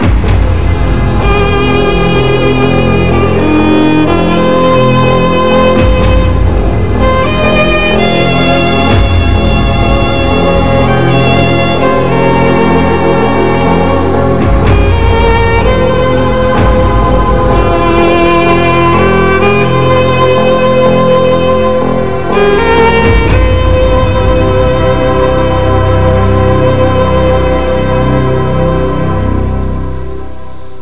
TV show Theme